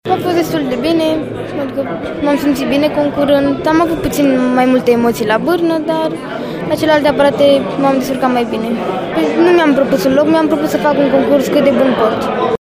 Le puteți asculta alături pe cele două foarte tinere gimnaste ale noastre cu impresii după competiție